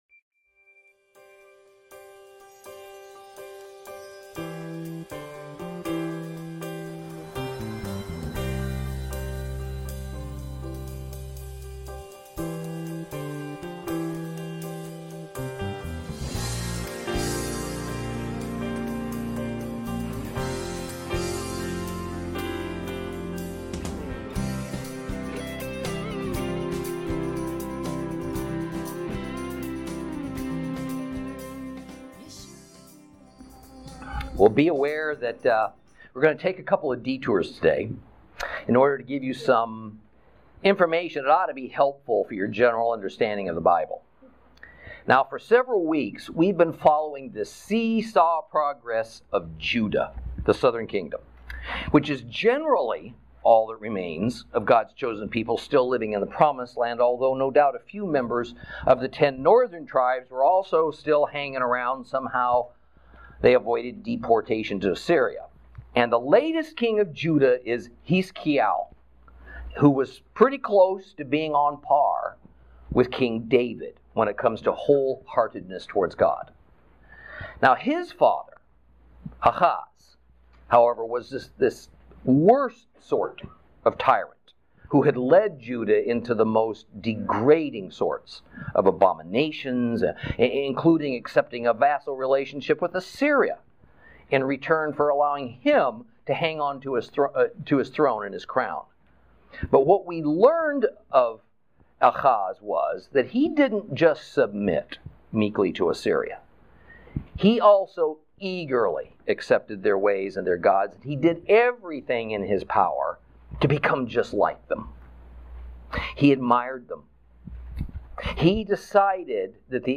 Lesson 30 Ch20 - Torah Class